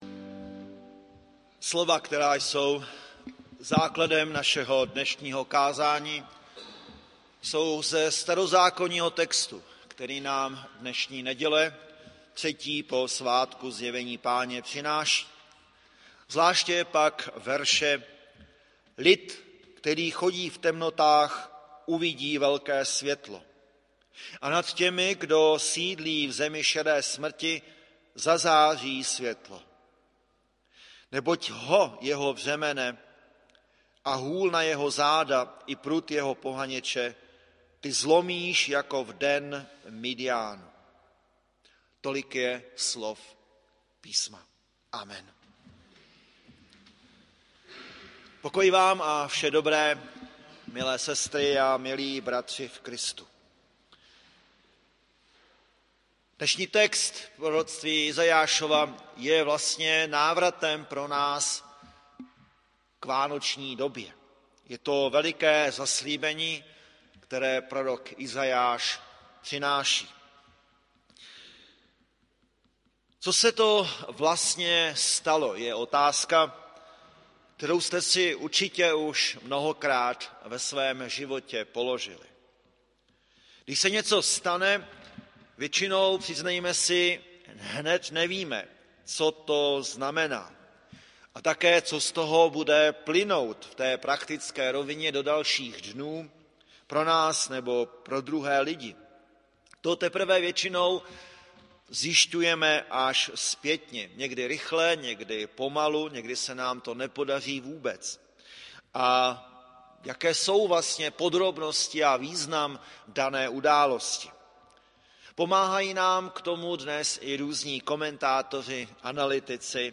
Bohoslužby 26.1.2020 • Farní sbor ČCE Plzeň - západní sbor